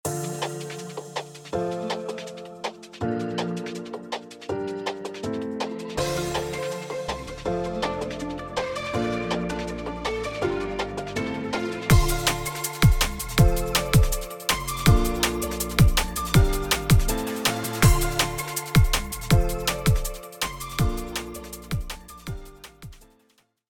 ドラムンベース×ドリル